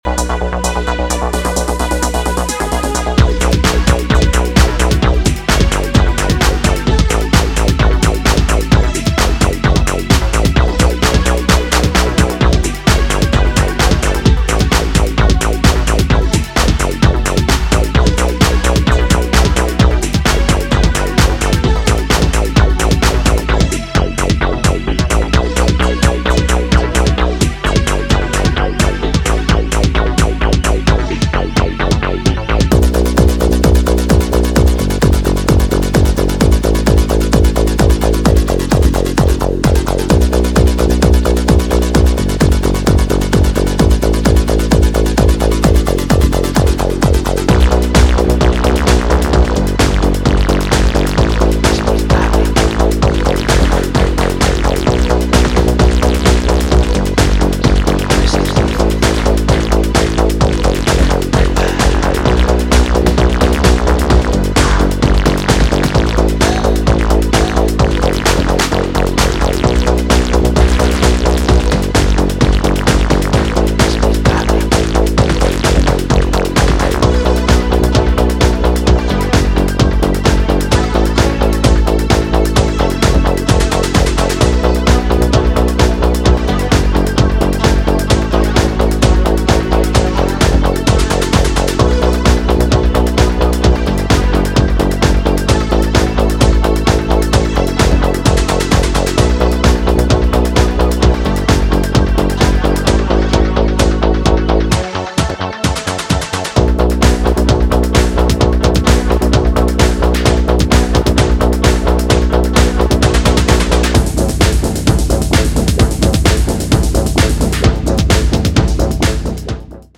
Disco Electro House Wave